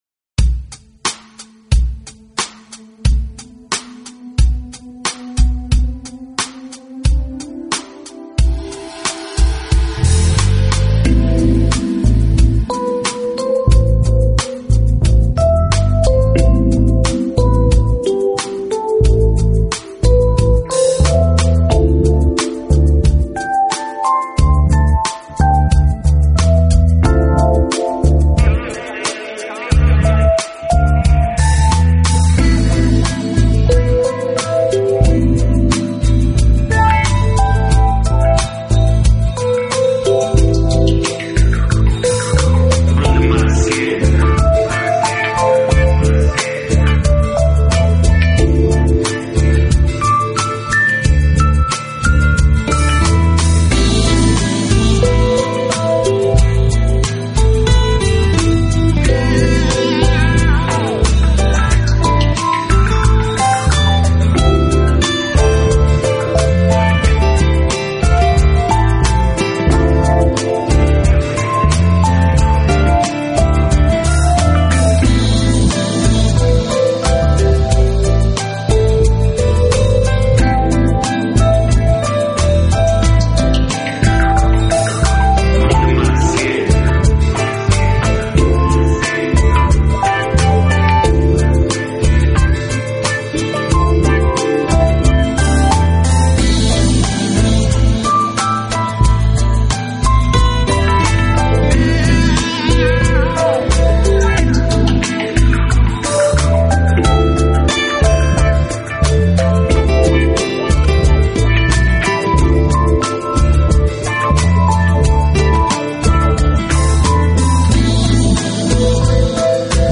音乐类型: Chill-Out, Lo-Fi, House